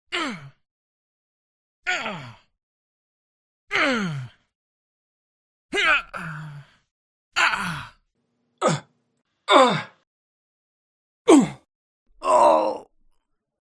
Male Pain Grunts Sound Effects SFX Free and Royalty Free.mp3